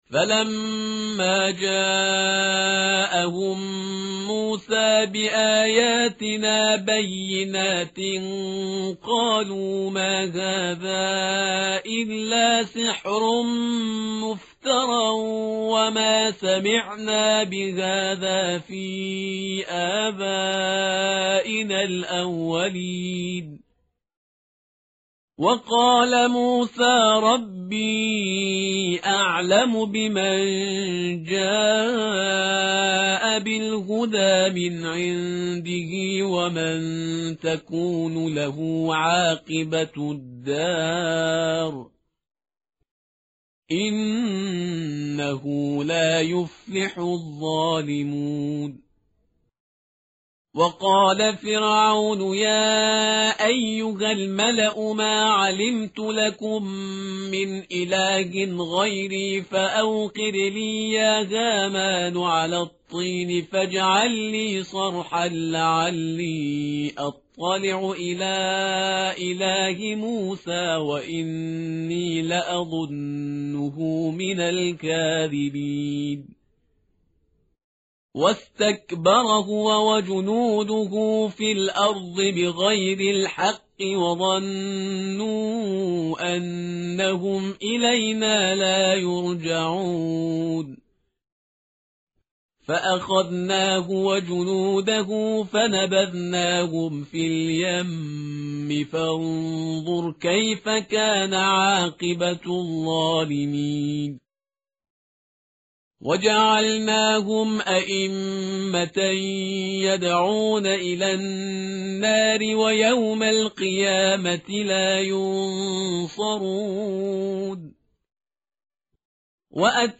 متن قرآن همراه باتلاوت قرآن و ترجمه
tartil_parhizgar_page_390.mp3